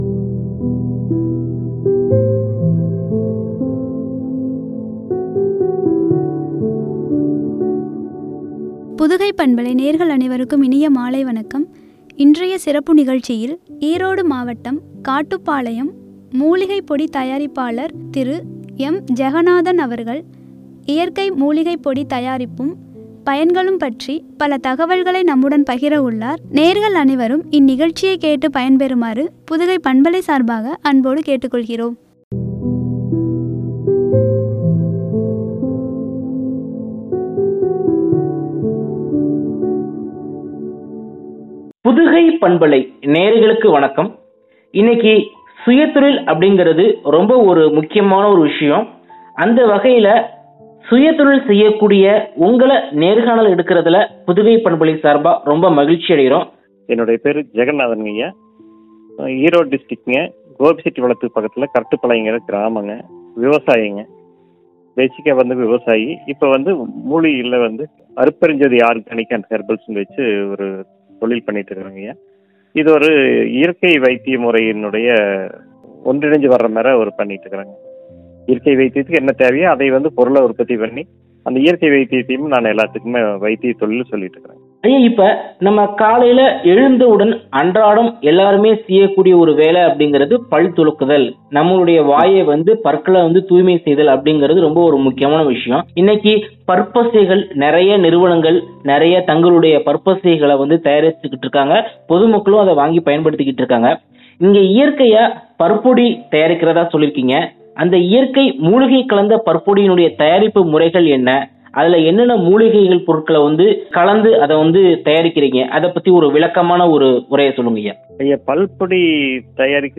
பயன்களும்” குறித்து வழங்கிய உரையாடல்.